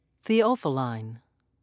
(thee-off'i-lin)